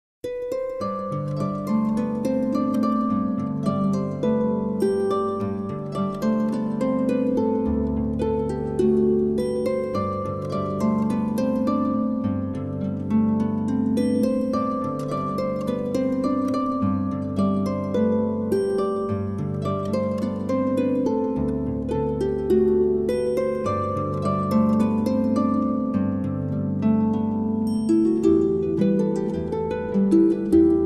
harp, piano
guitar
piano, sampler